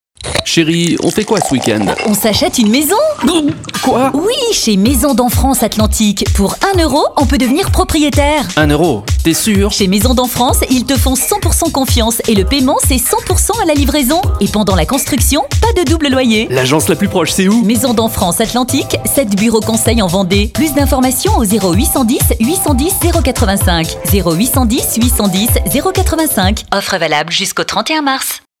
Faîtes appel à Maisons d'en France! Le constructeur nous fait confiance de la réalisation du texte au montage sur musique!